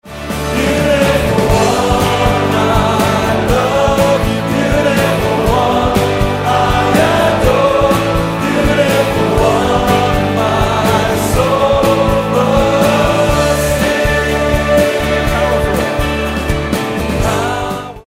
the chorus feels more relaxed and predictable.